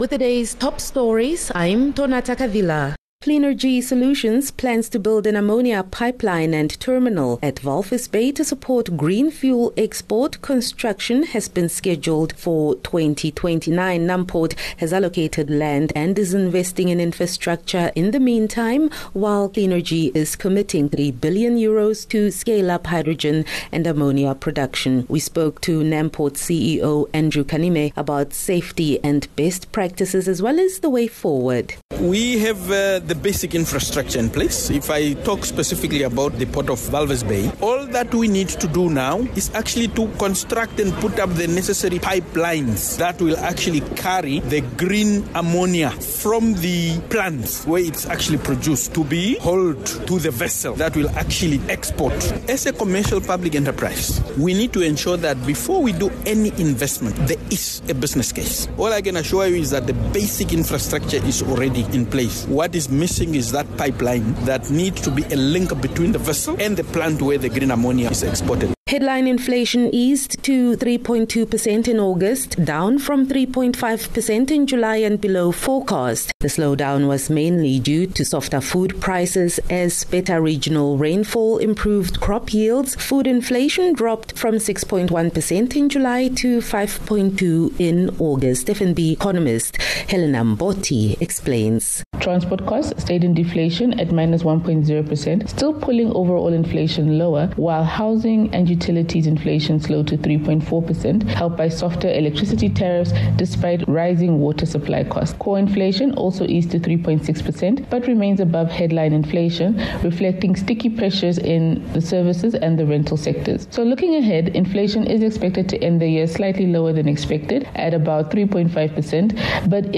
22 Sep 22 September - Top Stories